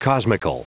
Prononciation du mot cosmical en anglais (fichier audio)
Prononciation du mot : cosmical